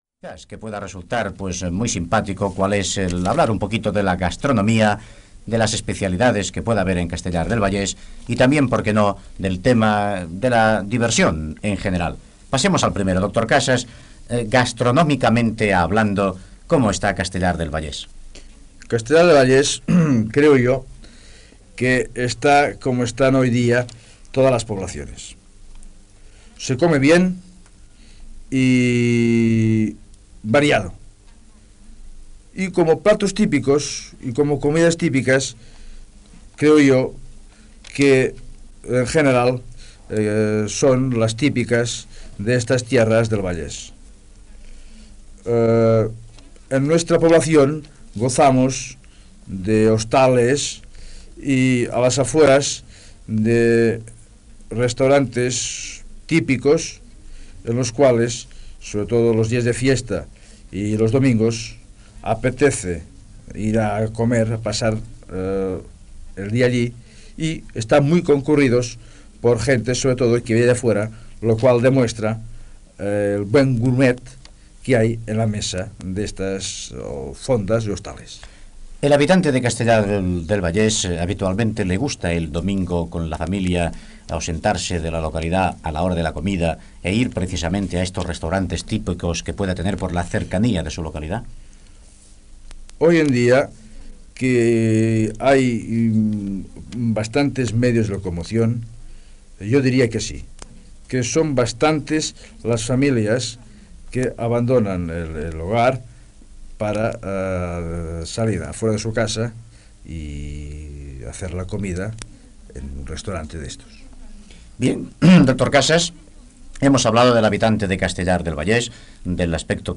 Entrevista al doctor Lorenzo Casas, alcalde de Castellar del Vallès, S'hi parla sobre la gastronomia i l'entreteniment a la localitat